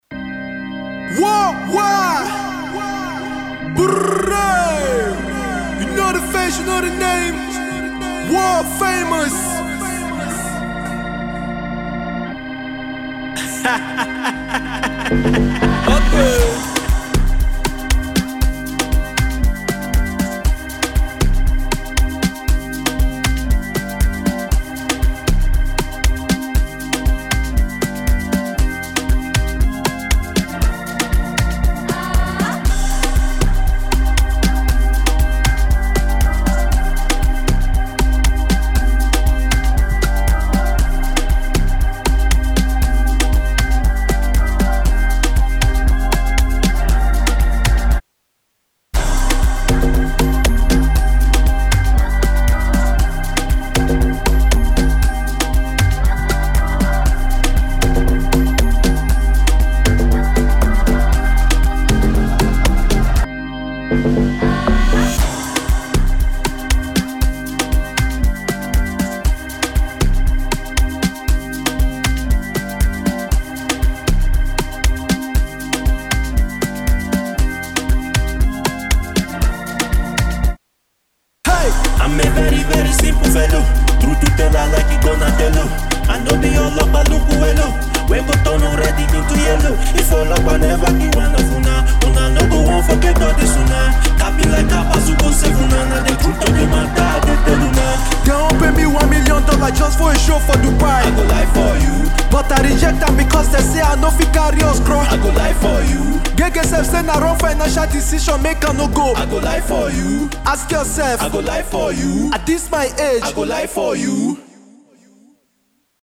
LISTEN AND DOWNLOAD OPEN VERSE HERE